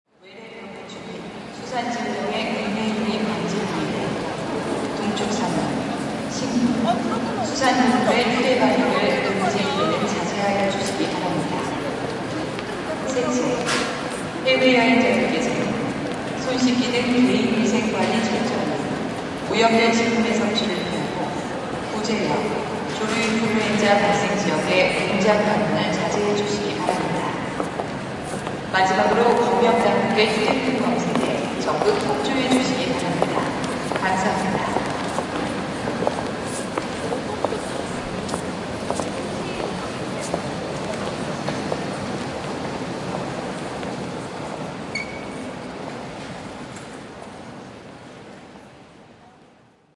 Download Airport sound effect for free.
Airport